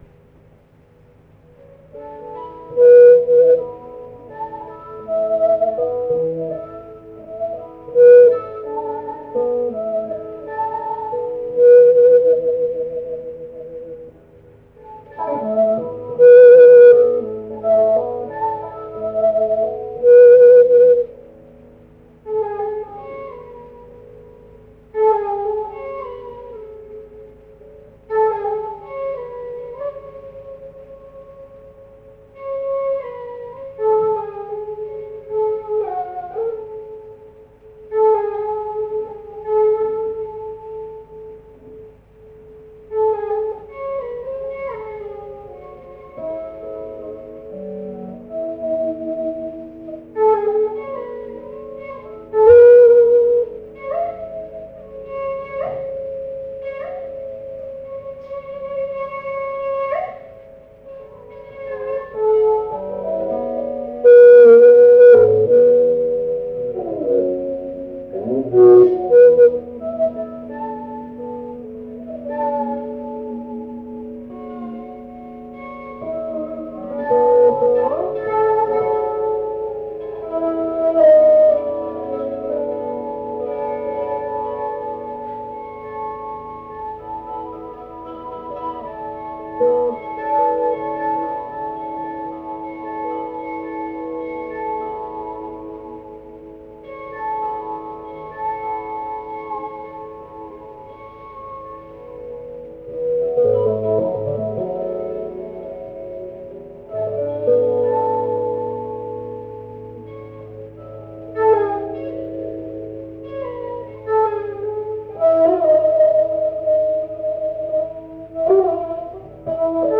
(注：｢ふせた方が面白いでしょ」ってことなんで……。でもヒントを言うと、'85.10.20のライヴからの抜粋です｡）